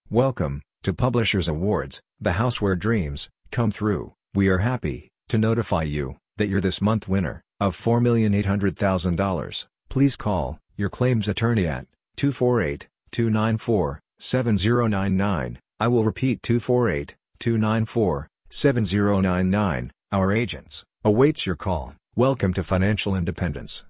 new robocall